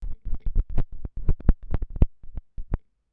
cleaning vinyl needle - Gm.wav
Sound effects
cleaning_vinyl_needle_-_Gm_-_123_l95.wav